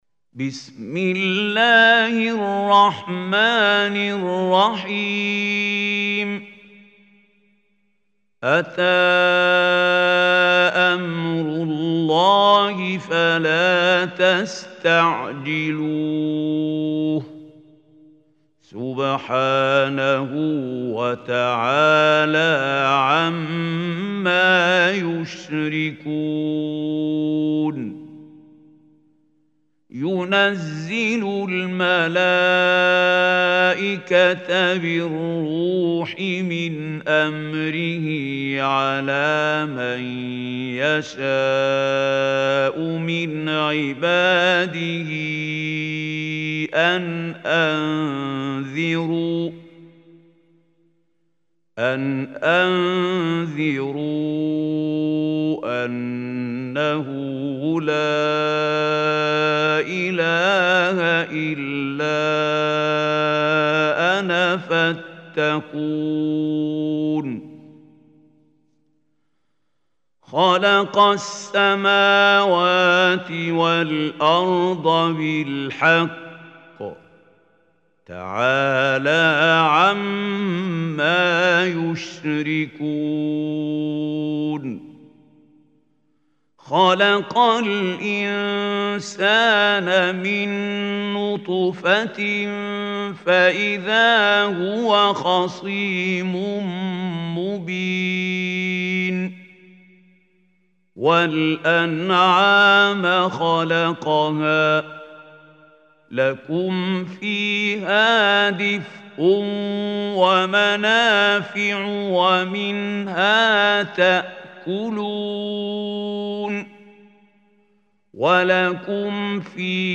Surah An Nahl Recitation by Khalil Al Hussary
Listen online and download Quran tilawat / recitation of Surah An Nahl in the beautiful voice of Mahmoud Khalil Al Hussary.